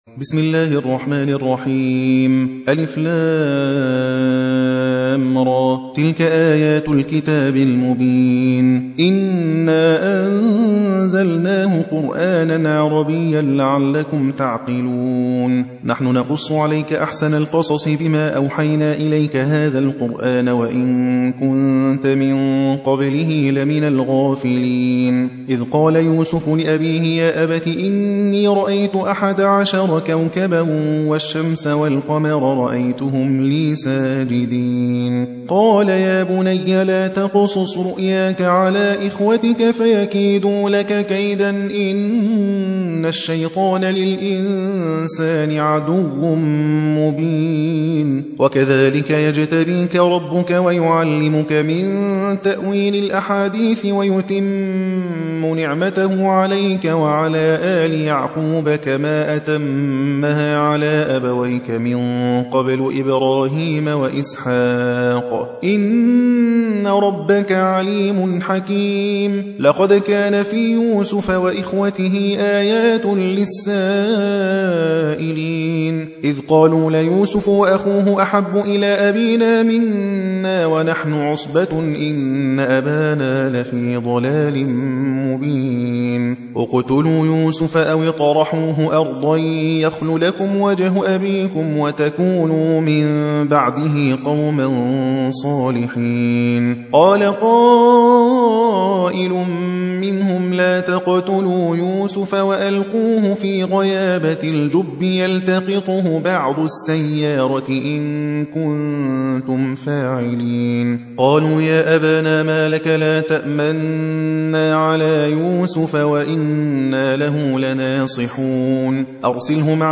12. سورة يوسف / القارئ